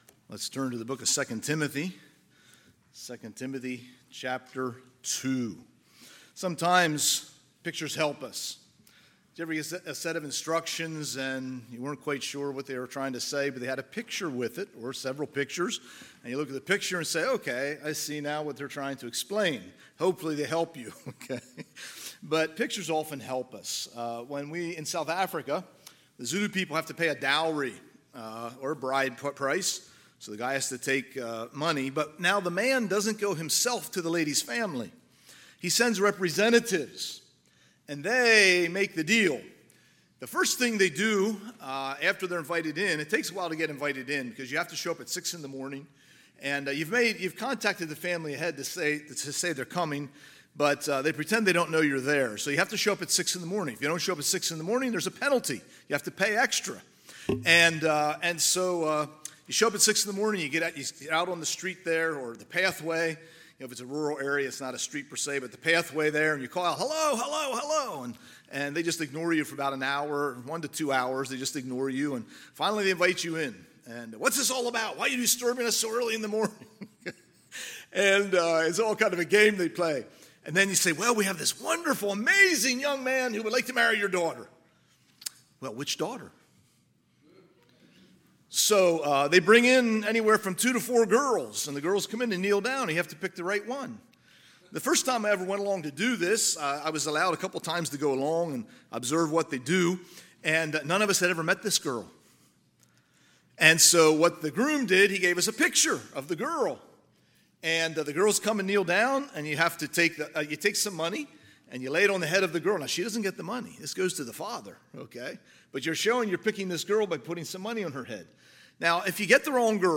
Sunday, August 4, 2024 – Sunday PM